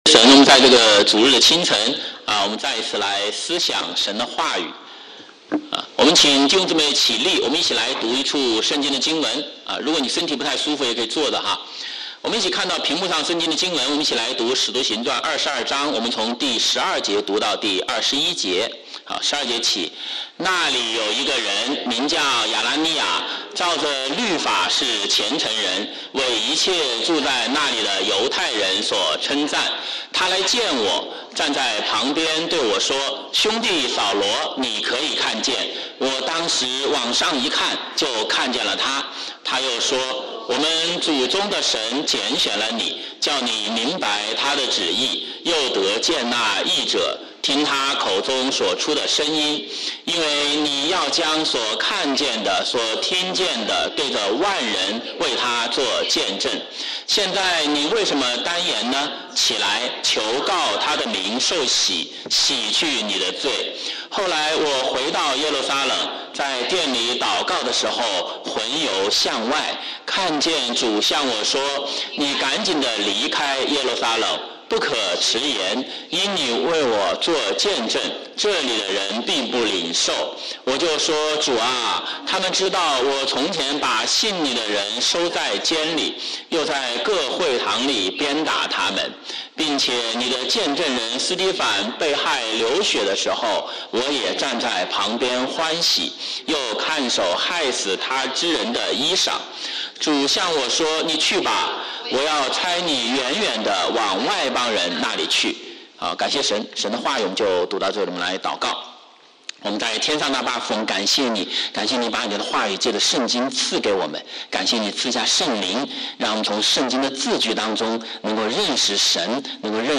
Sunday Sermons (Chinese)